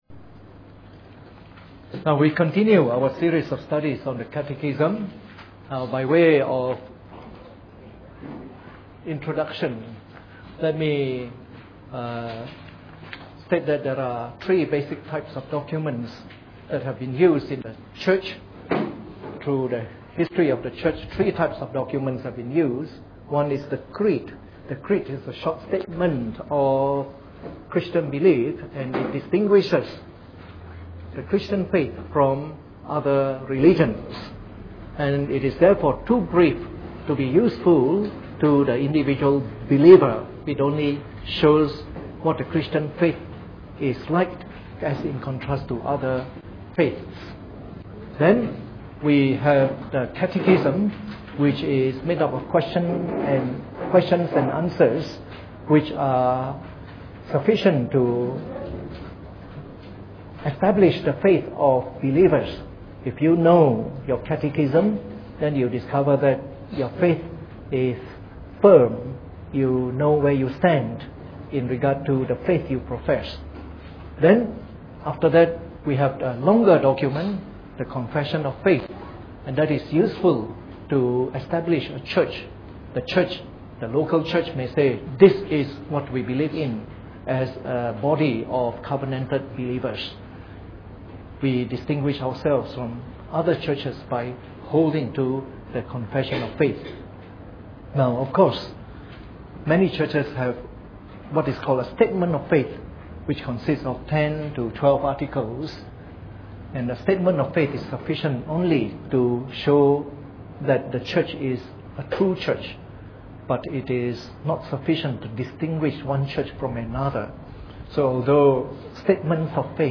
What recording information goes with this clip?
Preached on the 9th of February 2011 during the Bible Study from our current series on the Shorter Catechism.